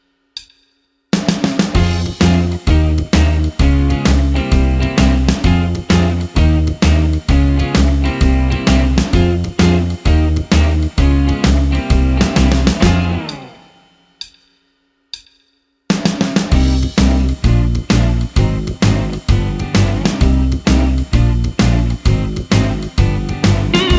Minus Solo Guitar Rock 'n' Roll 3:21 Buy £1.50